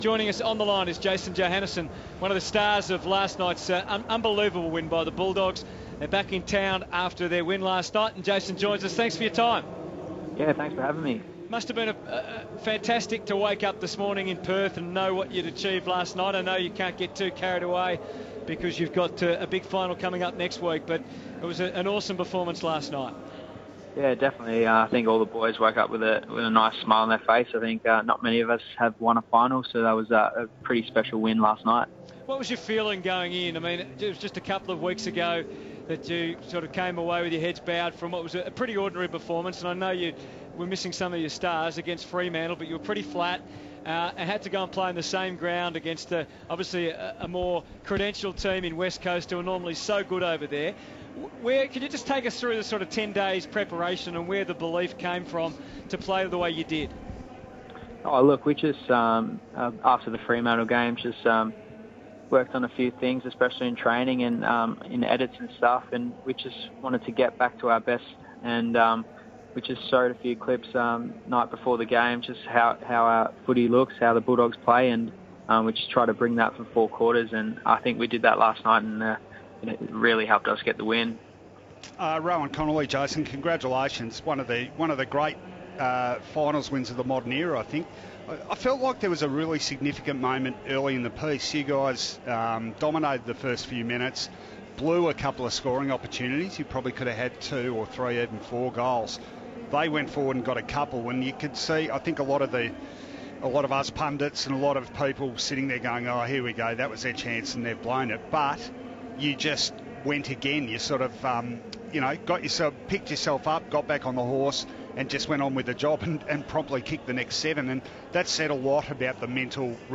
Western Bulldogs defender Jason Johannisen chats with the team following the Dogs Elimination Final win over West Coast